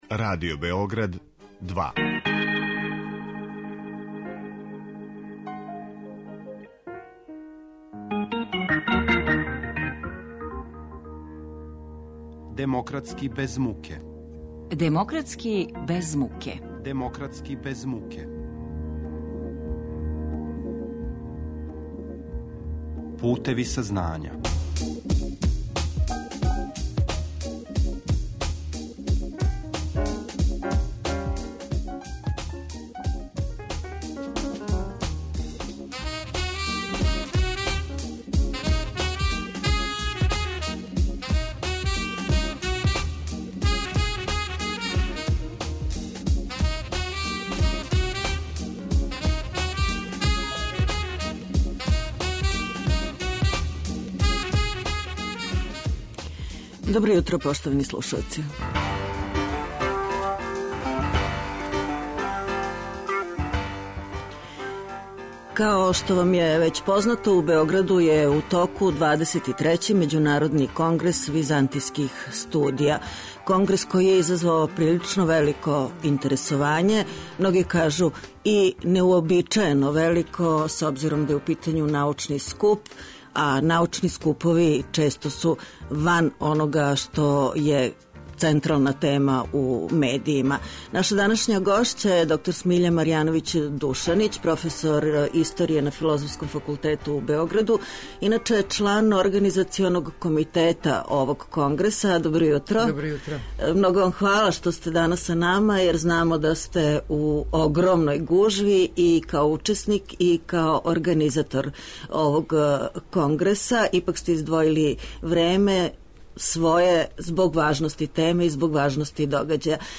О значају овог конгреса за Београд и Србију, говори гошћа емисије